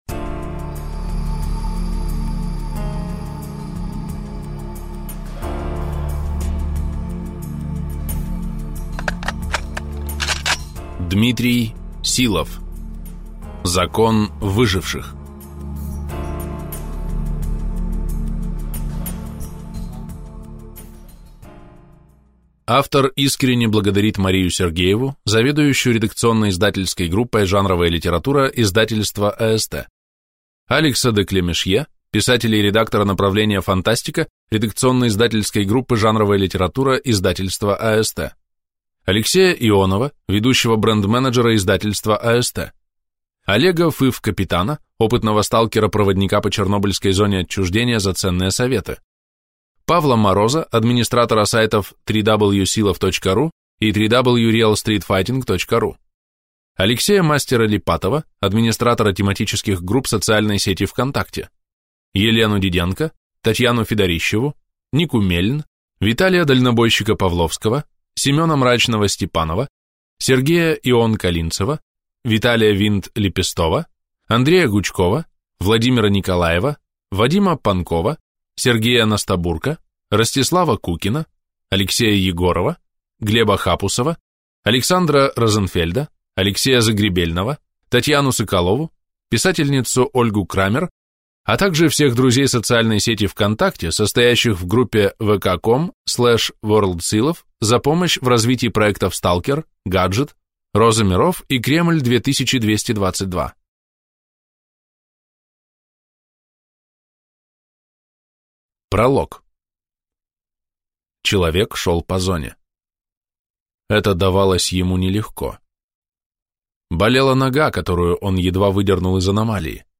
Аудиокнига Закон выживших | Библиотека аудиокниг